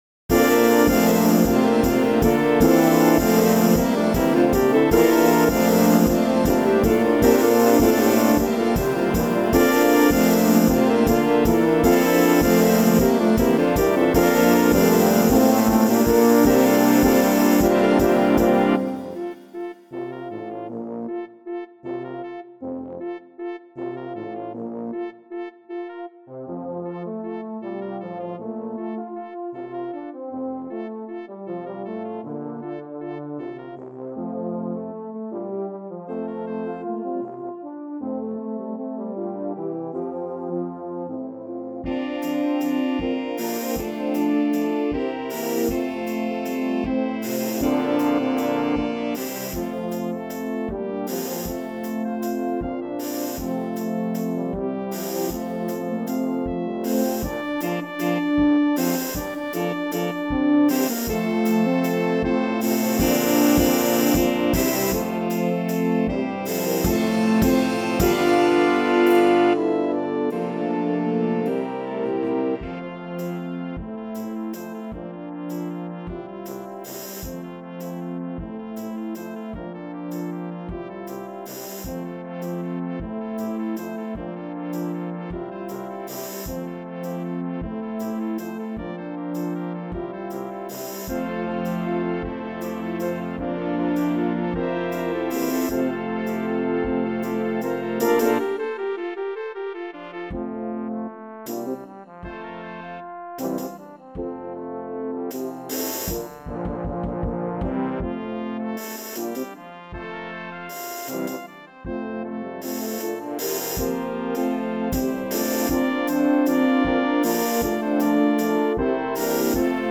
setting for brass band